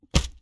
Close Combat Attack Sound 7.wav